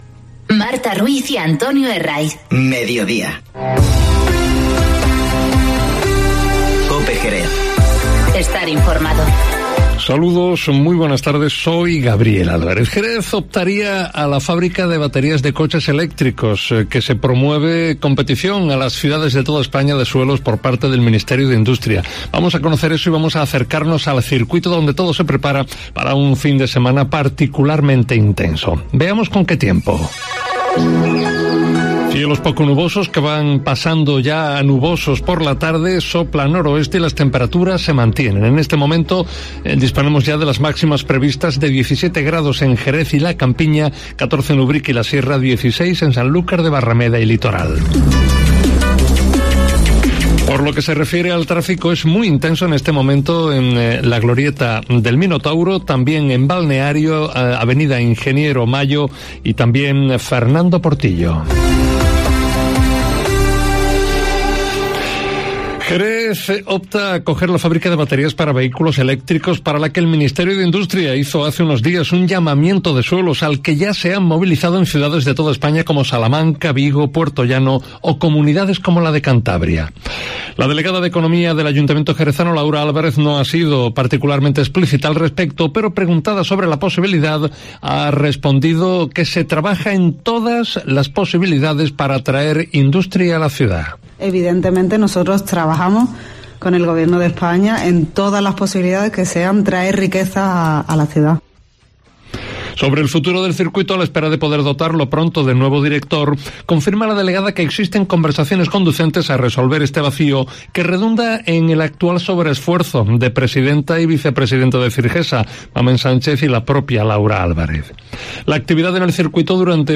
Boletines COPE